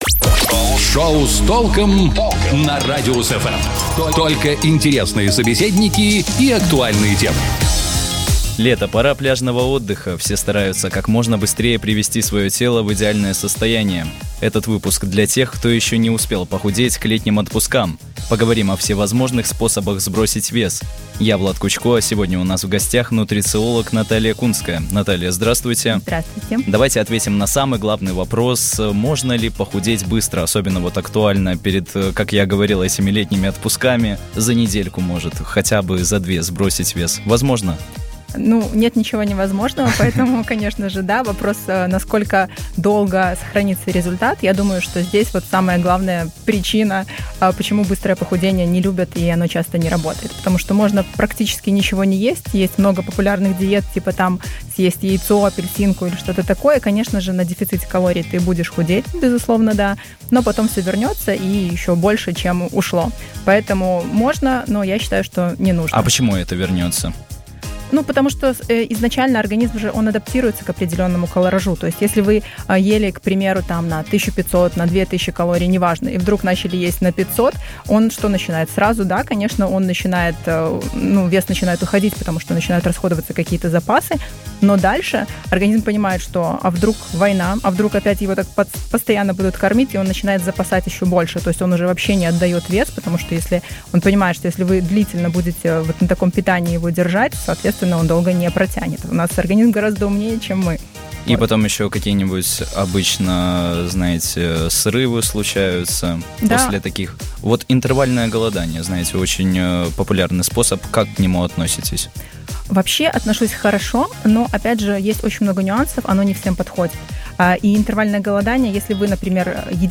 Речь пойдет об эффективных способах сбросить вес. Обо всем этом расскажет нутрициолог